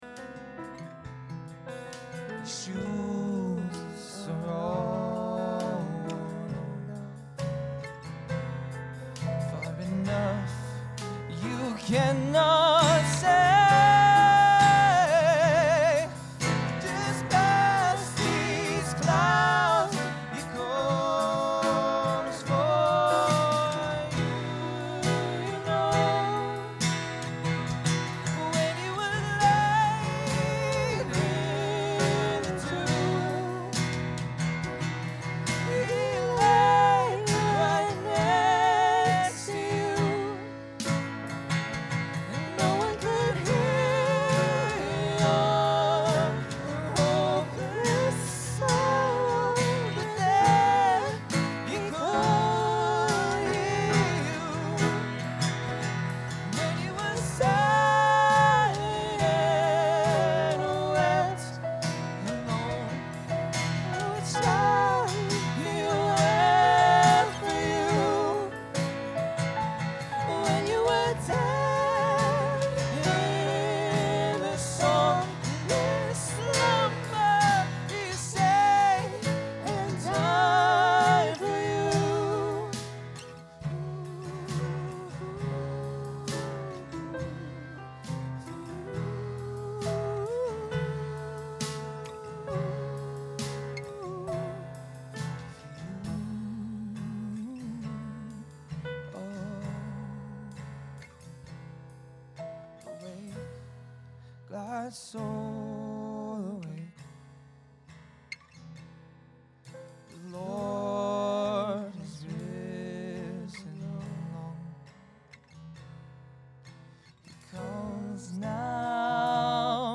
Blue Christmas Service